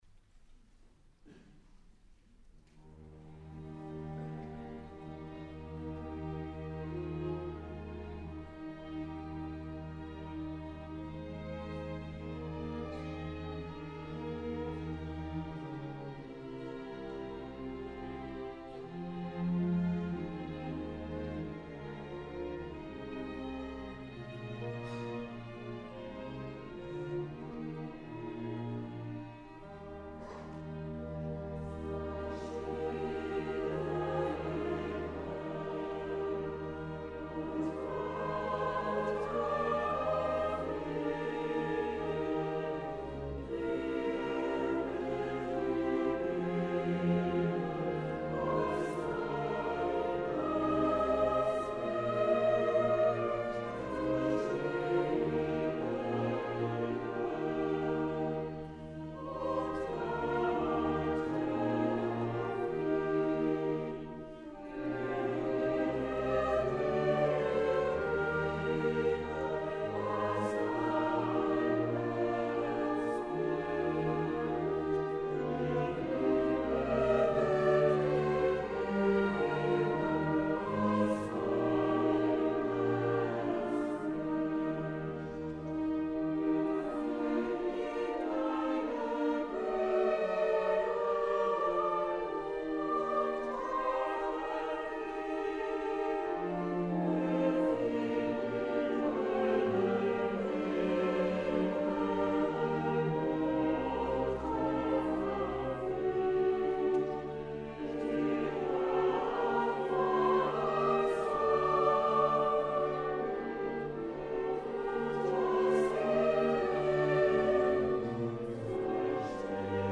Weihnachtskonzert im Neuen Gewandhaus zu Leipzig   Freitag, 07.
Orchester des Sinfonischen Musikvereins Leipzig e. V.